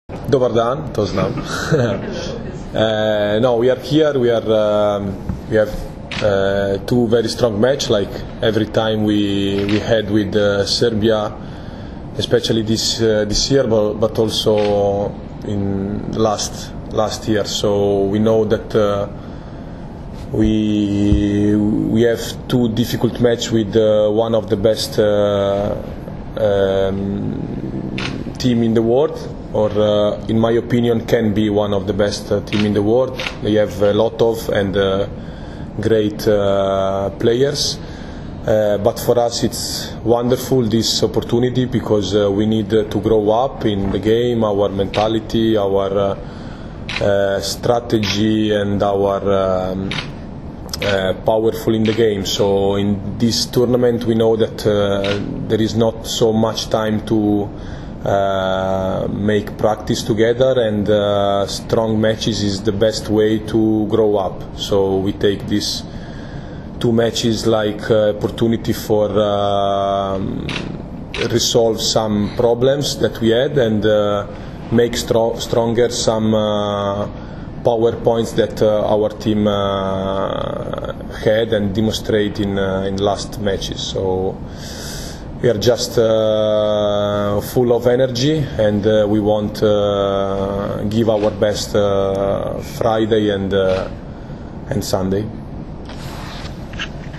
U novosadskom hotelu “Sole mio” danas je održana konferencija za novinare, kojoj su prisustvovali Dragan Stanković, Nikola Grbić, Dragan Travica i Mauro Beruto, kapiteni i treneri Srbije i Italije.
IZJAVA DRAGANA TRAVICE